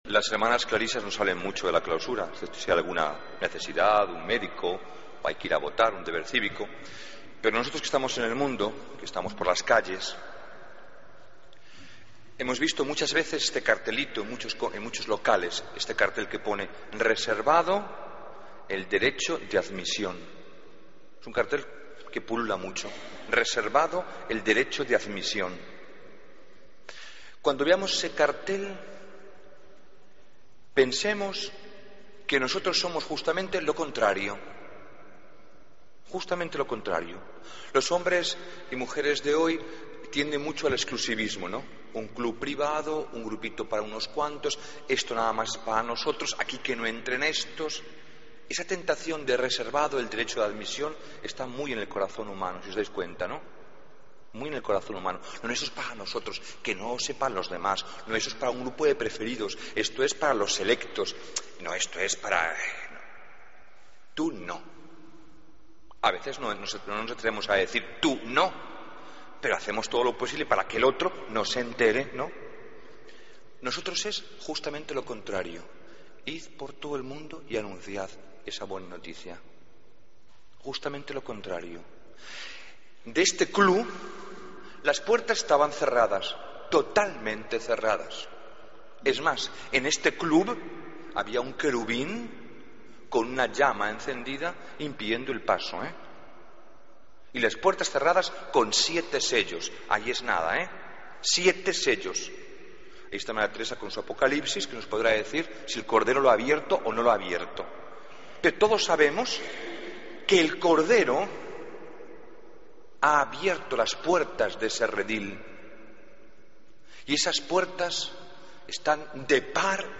Homilía del 25 de agosto de 2013